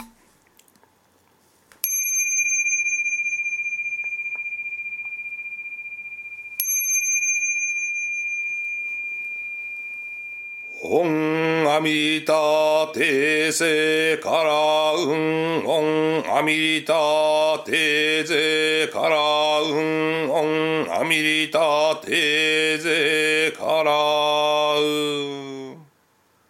お経は、頭で覚えて読むものではなく、経本を読みながら一字一句間違えが唱えるのがお経ですが、節回し等、各寺院・僧侶によって異なりますのでご注意ください。